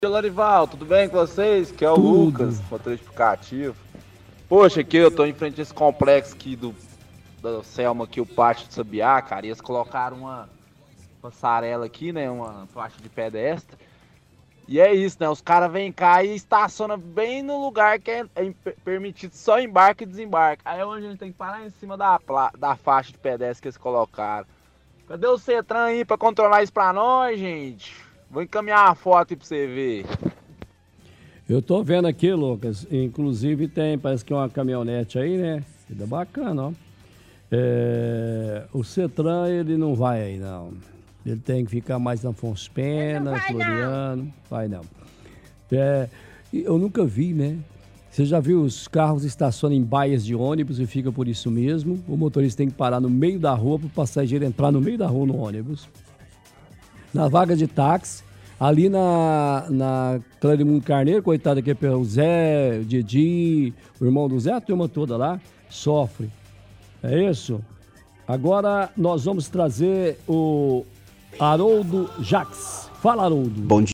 – Ouvinte motorista de aplicativo reclama da passarela no complexo do Sabiá, fala que pessoas estacionam de forma irregular, estacionam em paradas de ônibus, ouvinte cobra o SETTRAN.